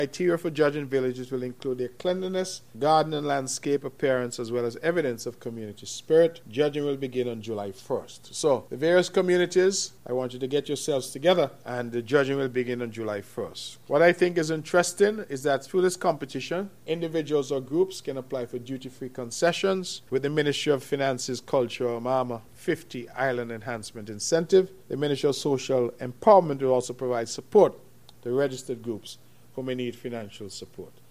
Villages will be judged by a number of factors. Giving us more was Premier Hon. Mark Brantley: